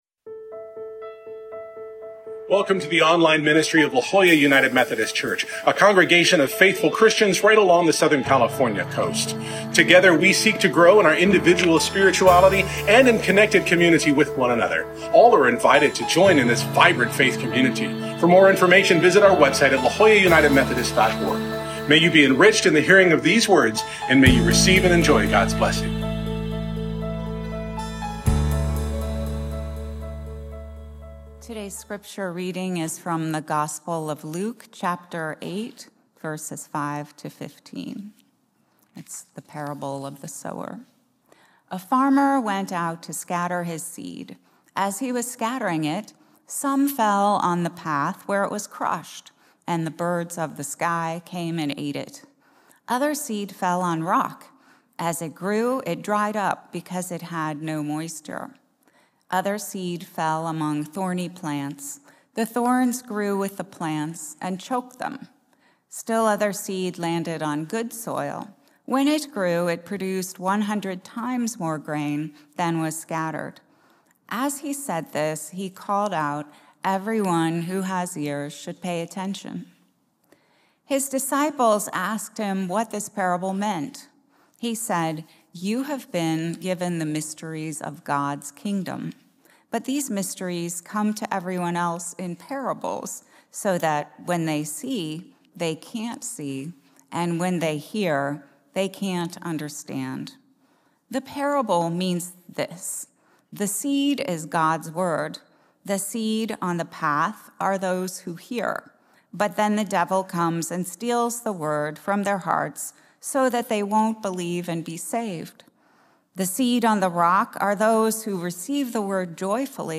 Scripture: Luke 8:5-15 (CEB) Order of Worship sermon Note Full Worship Video Share this: Print (Opens in new window) Print Share on X (Opens in new window) X Share on Facebook (Opens in new window) Facebook